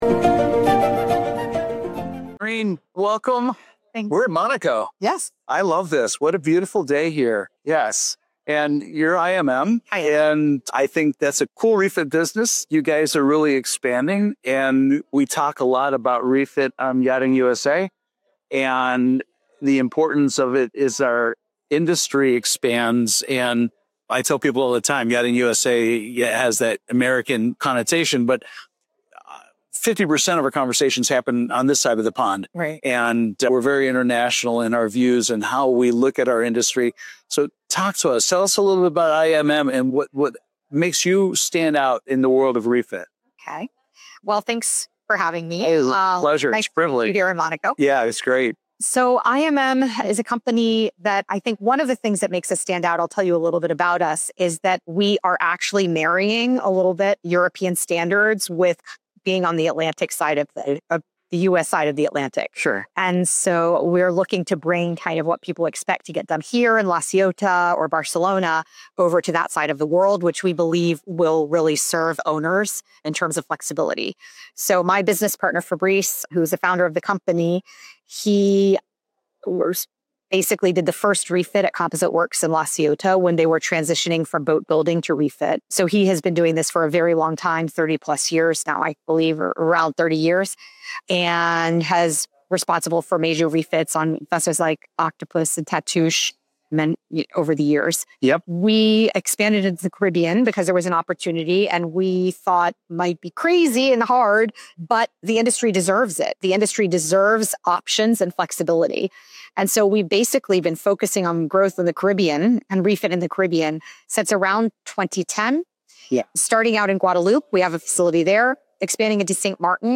Watch more exclusive interviews from the Monaco Yacht Show on Yachting International Radio — the voice of the global superyacht community.